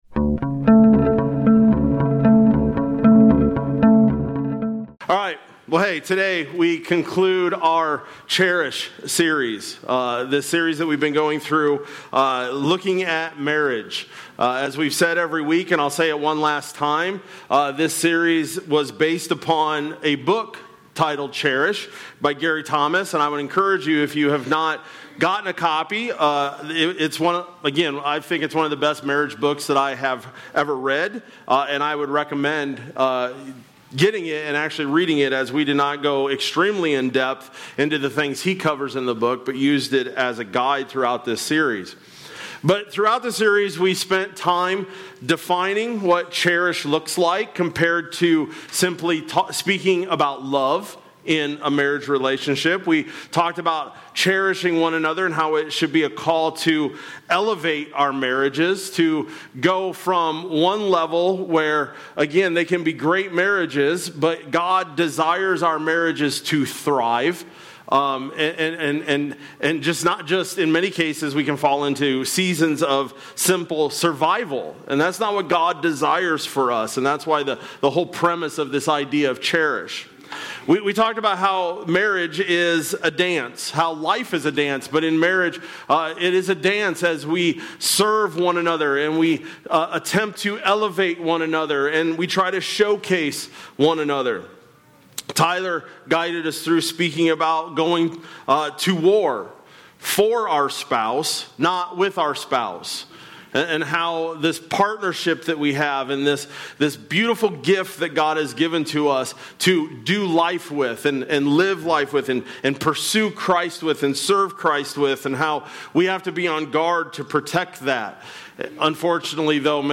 Oct-12-25-Sermon-Audio.mp3